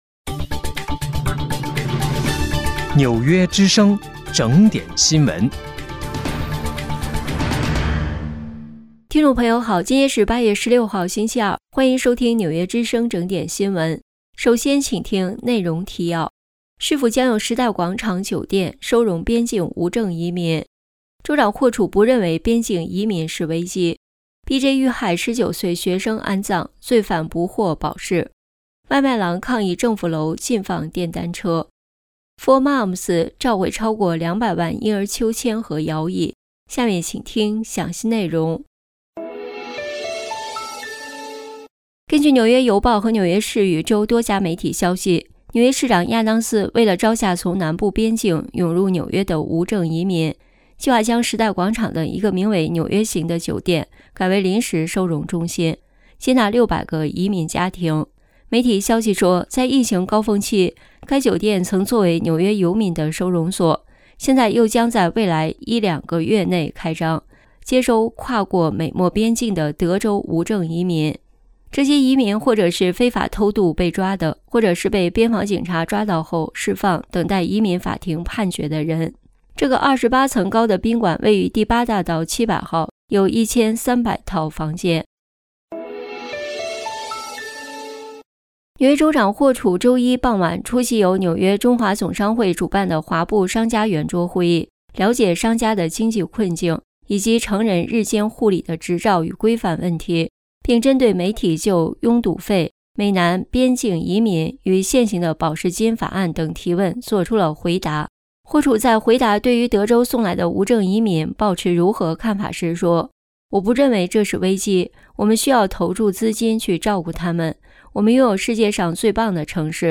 8月16日（星期二）纽约整点新闻